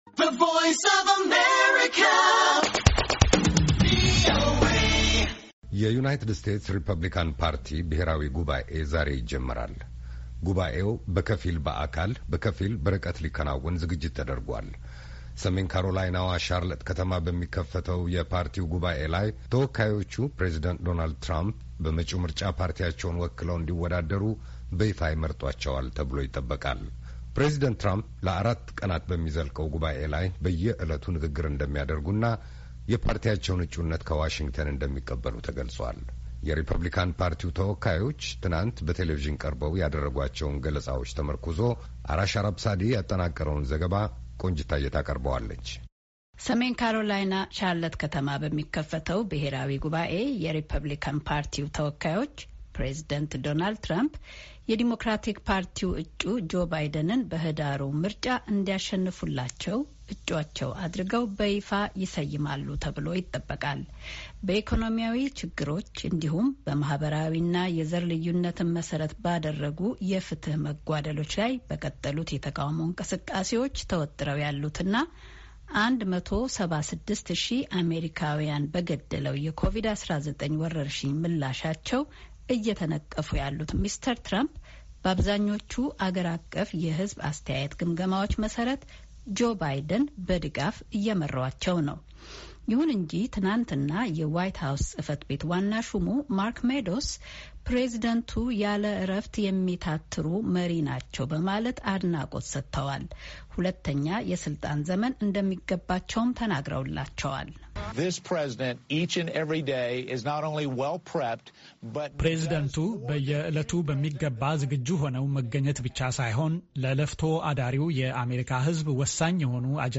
ያጠናቀረውን ዘገባ ይዘናል።